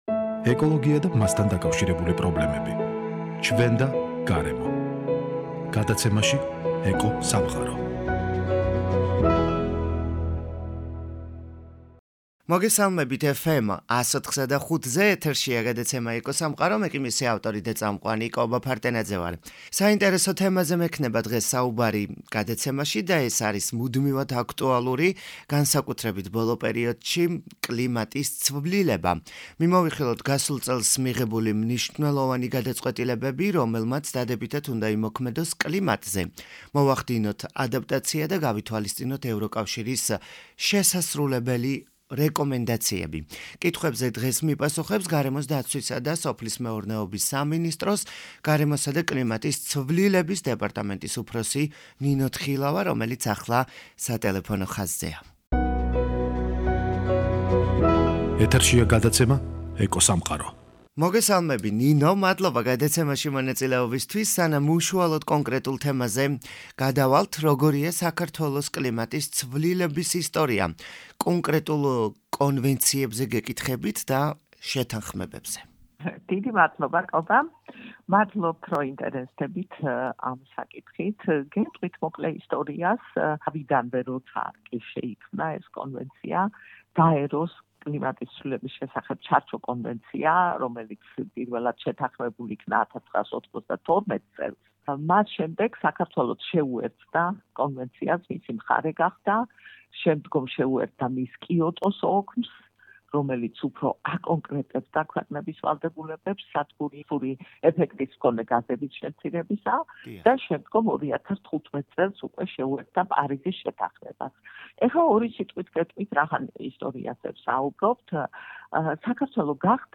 კითხვებზე მპასუხობს